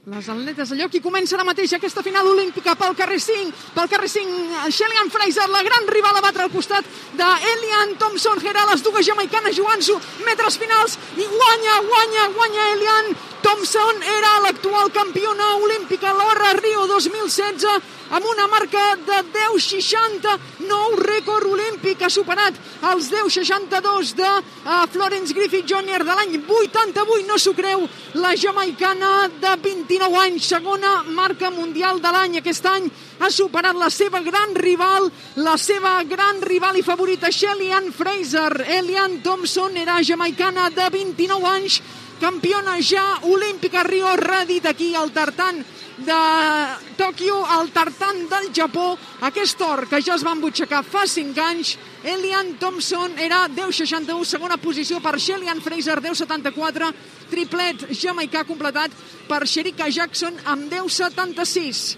Transmissió de la final femenina dels 100 metres llisos dels Jocs Olímpics de Tokyo.
Esportiu